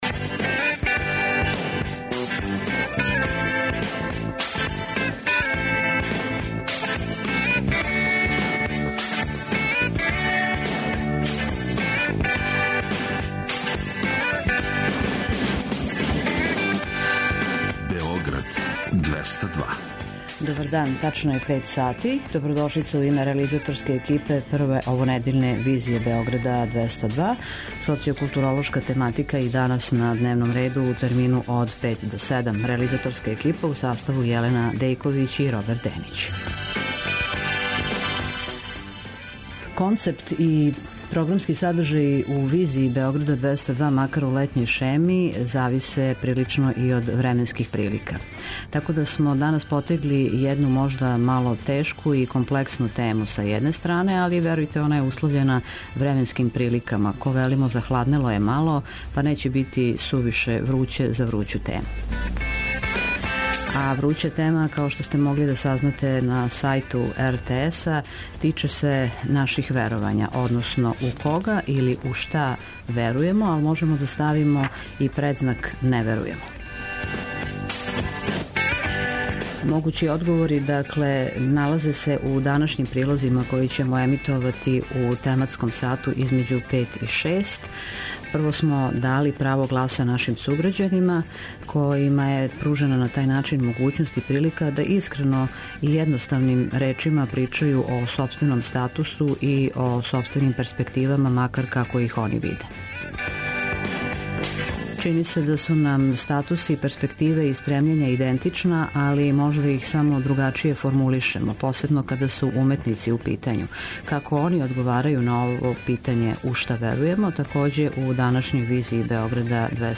На постављено питање поред суграђана, којима је пружена прилика да искрено причају о свом статусу и перспективи, говориће и уметници.
преузми : 56.20 MB Визија Autor: Београд 202 Социо-културолошки магазин, који прати савремене друштвене феномене.